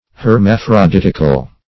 \Her*maph`ro*dit"ic*al\, a.